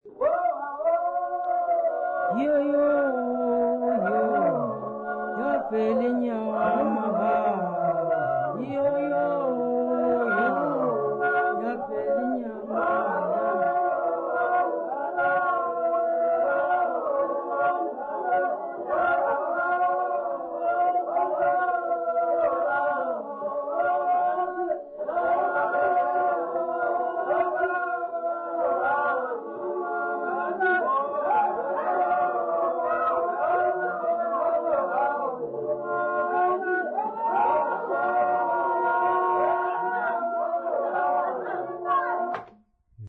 Xhosa women
Folk music South Africa
Africa South Africa Lumko, Eastern Cape sa
field recordings
Unaccompanied traditional Xhosa song.